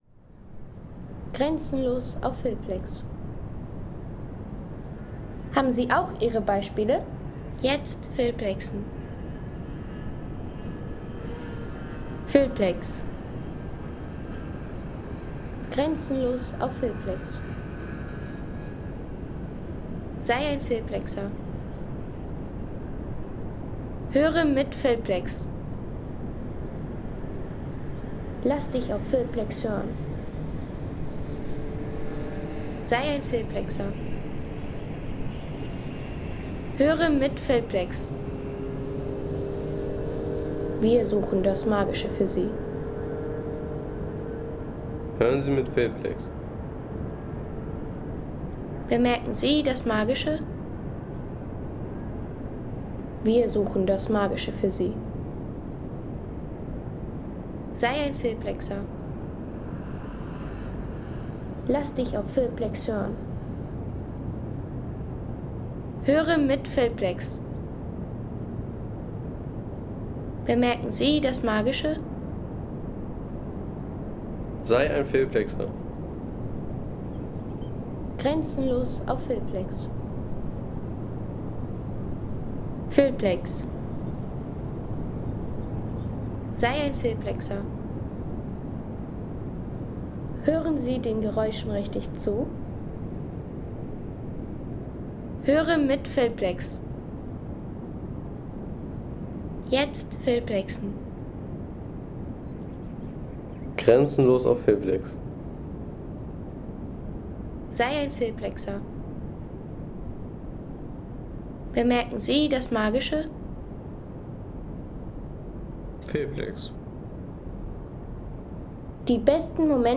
Landschaft - Flüsse
Am Fluss Otra nahe der norwegischen Ortschaft Evje erwartet Sie ein ru ... 3,50 € Inkl. 19% MwSt.